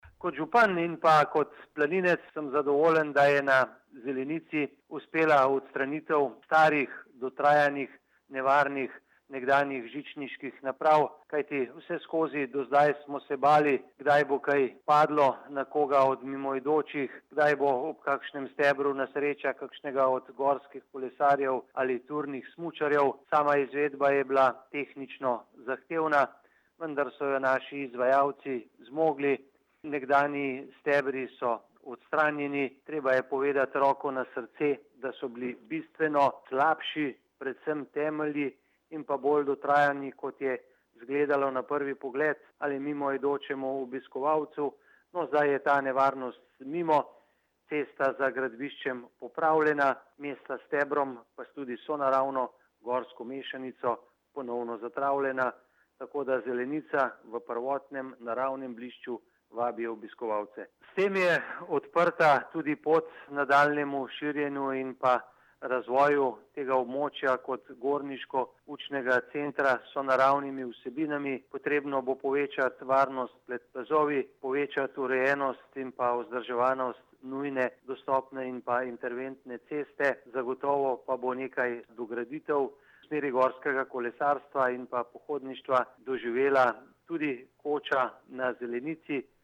93944_izjava_zupanobcinetrzicmag.borutsajovicozelenici.mp3